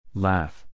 L at the beginning of a word or syllable (or before a vowel) is the light L, such as in